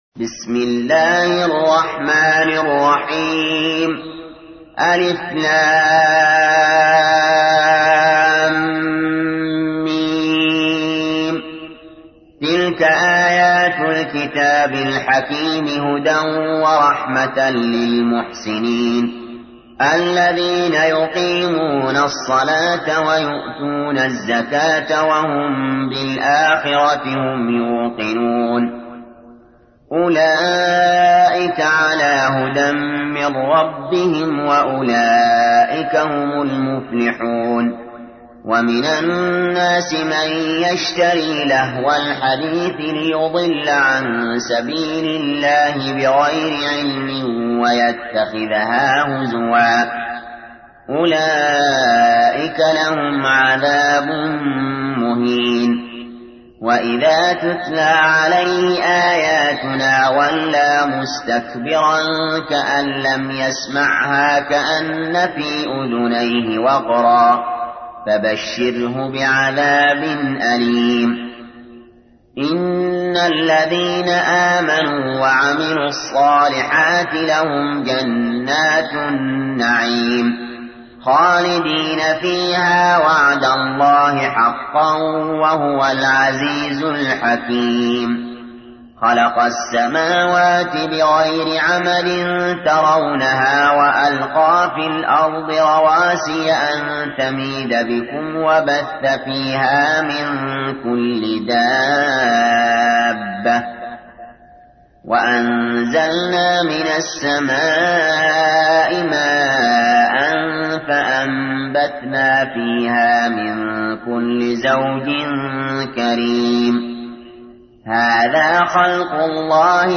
تحميل : 31. سورة لقمان / القارئ علي جابر / القرآن الكريم / موقع يا حسين